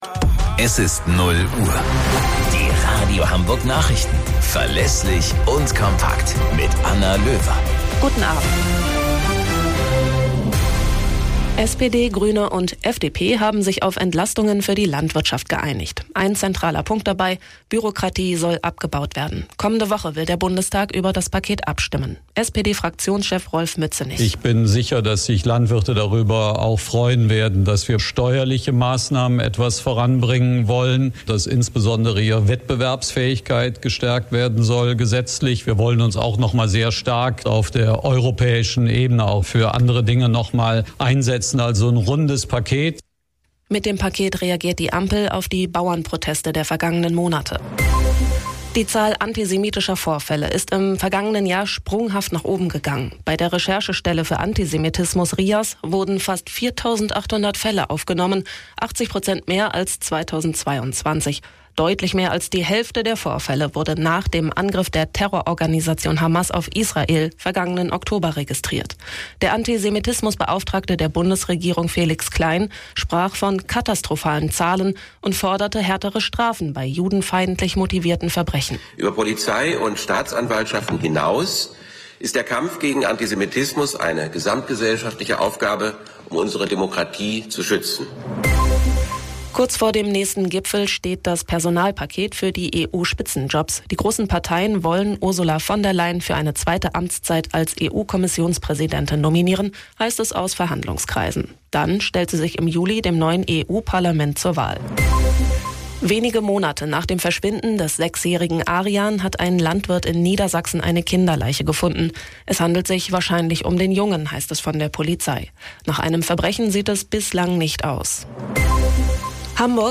Radio Hamburg Nachrichten vom 26.06.2024 um 02 Uhr - 26.06.2024